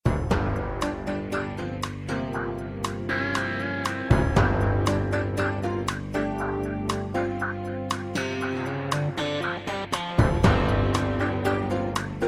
Voici 3 exemples de générique :